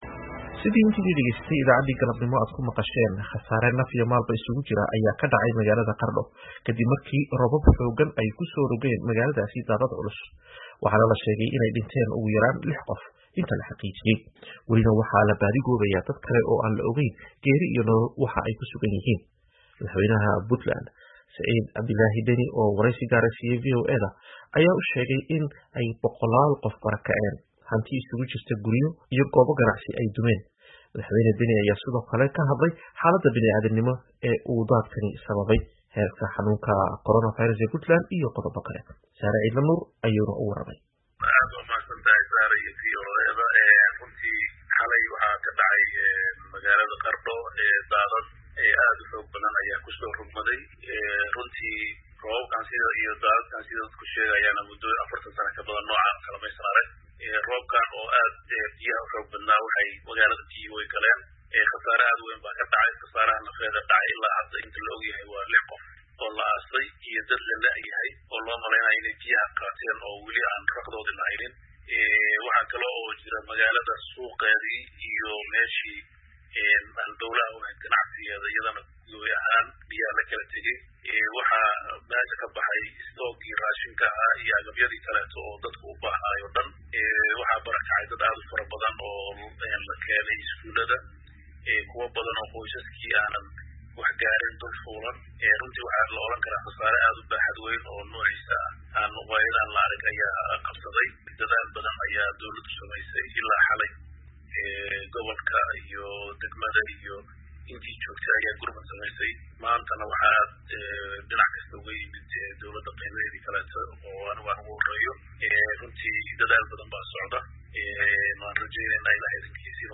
Wareysi: Deni oo ka warbixiyey fatahaadda Qardho
Madaxweynaha Puntland, Siciid Cabdilaahi Deni, oo waraysi gaar ah siiyay VOA, ayaa sheegay in ay boqolaal qof barakaceen, hanti iskugu jirta guryo la degenaa iyo goobo ganacsina ay dumeen.